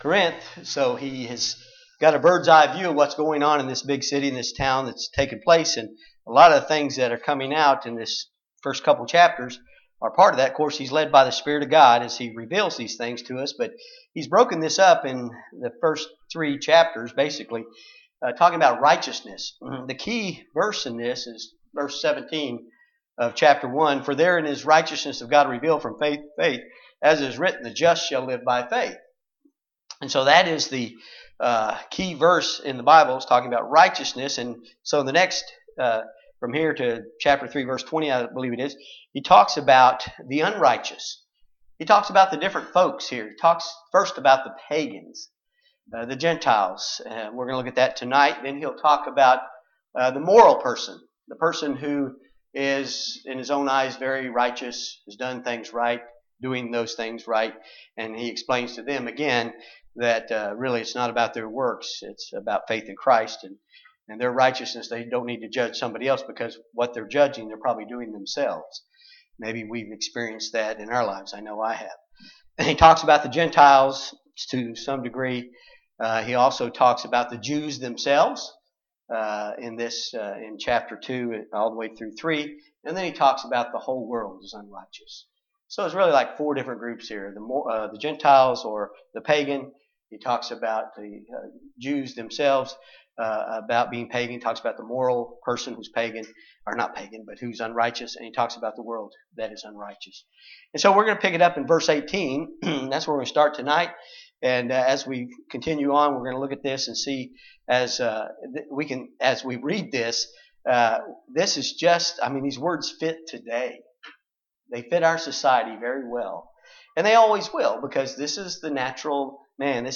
2020 A Study in the Book of Romans Passage: Romans 1:18-26 Service Type: Wednesday Bible Study